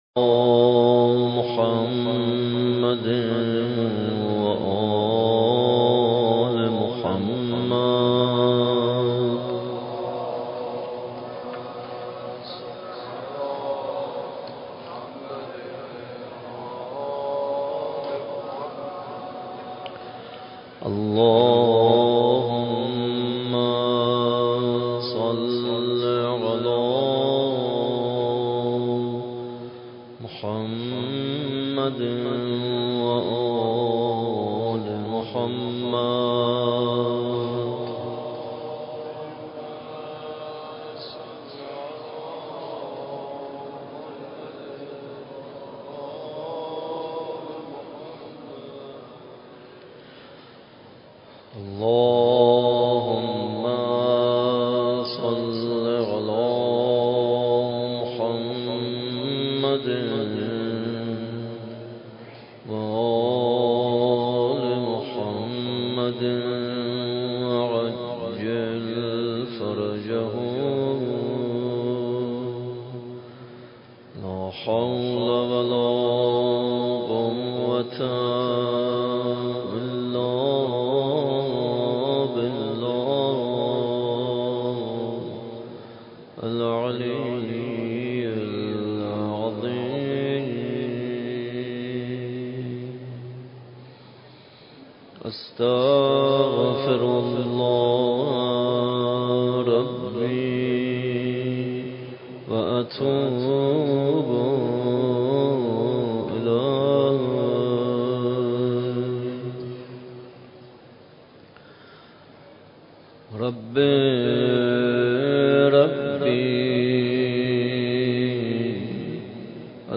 مراسم شب هفتم ماه مبارک رمضان با مداحی
در مسجد امام حسین (ع) واقع در میدان امام حسین(ع) برگزار گردید.
شب هفتم ماه مبارک رمضان دعای ابوحمزه لینک کپی شد گزارش خطا پسندها 0 اشتراک گذاری فیسبوک سروش واتس‌اپ لینکدین توییتر تلگرام اشتراک گذاری فیسبوک سروش واتس‌اپ لینکدین توییتر تلگرام